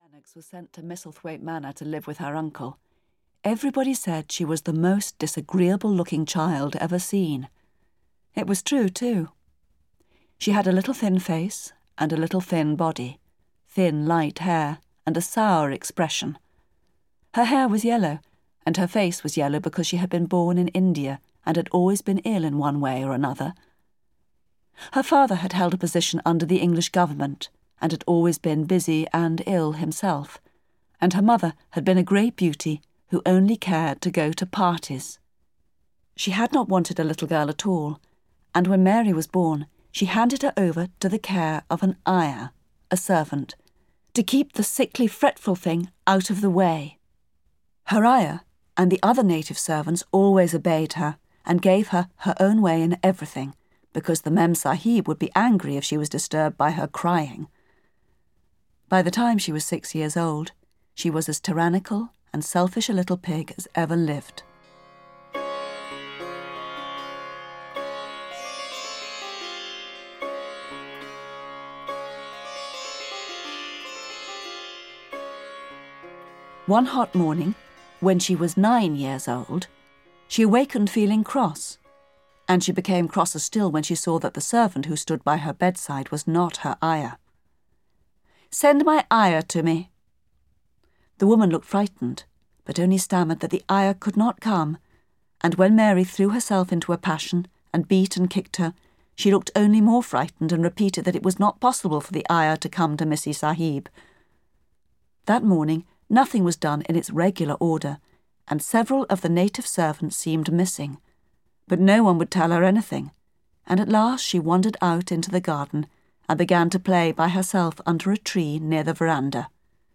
Audio kniha
• InterpretJenny Agutter